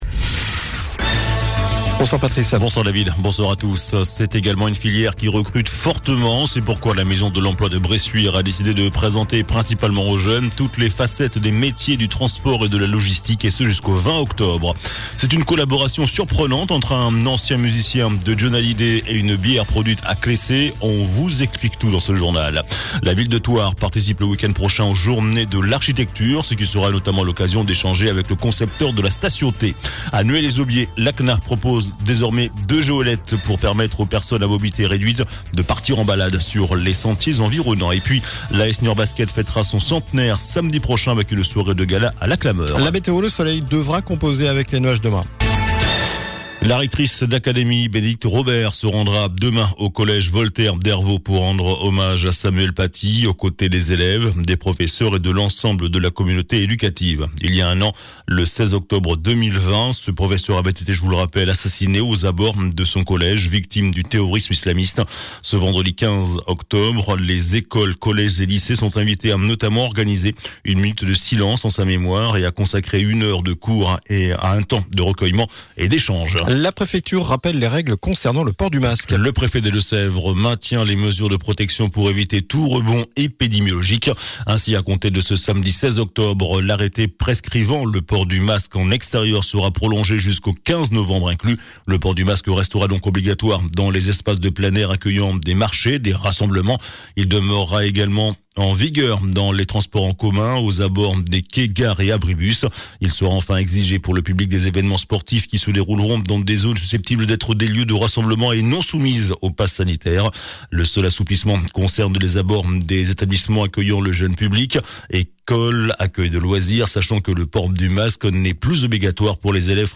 JOURNAL DU JEUDI 14 OCTOBRE ( SOIR )